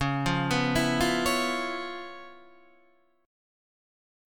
C#7#9 chord {9 8 9 9 6 9} chord